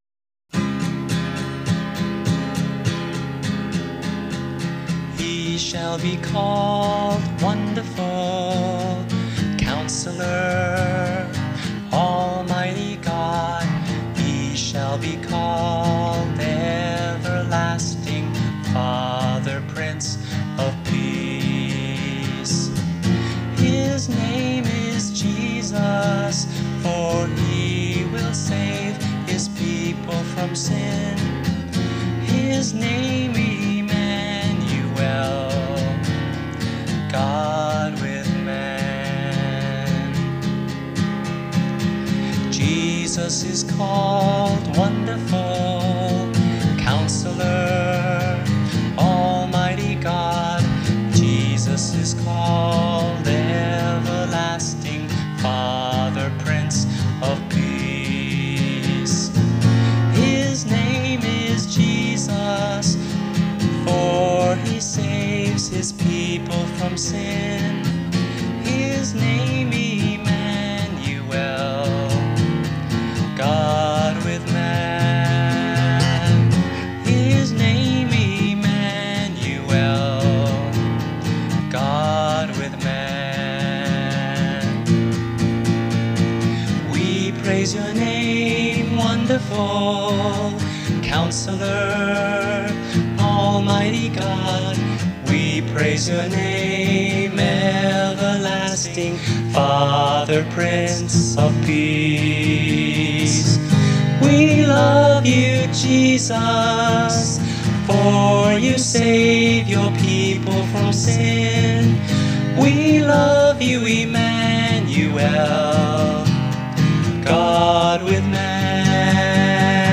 This Christmas carol has traveled the world for 30+ years!!!